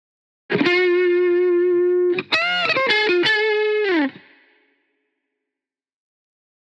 今回の記事の為にレコーディングした音源と合わせてご紹介します。（超短いフレーズですが）